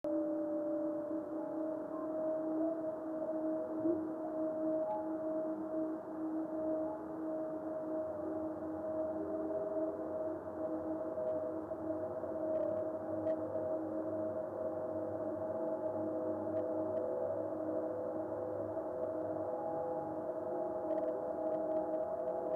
This fireball occurred during a previous radio fireball reflection so it is difficult to distinguish the head echo.